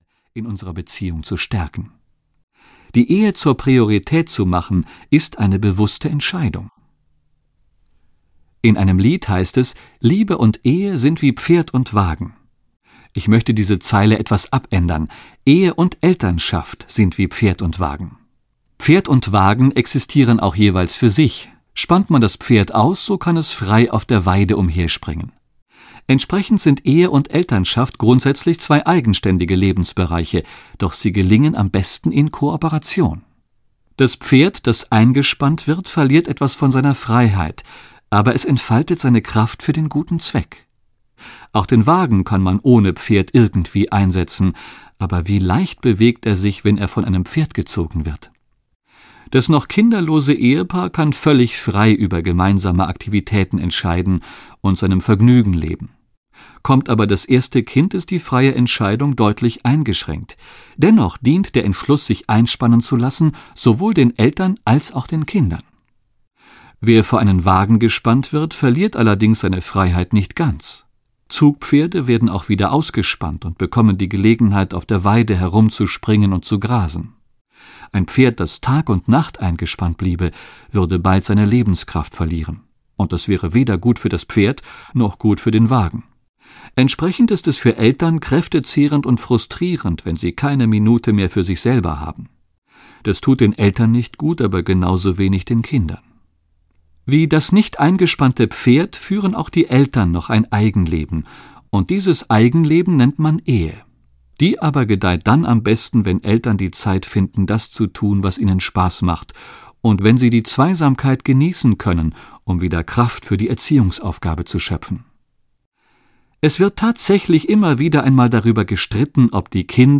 Schlafräuber und Wonneproppen - Gary Chapman - Hörbuch